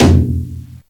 taiko-normal-hitfinish.ogg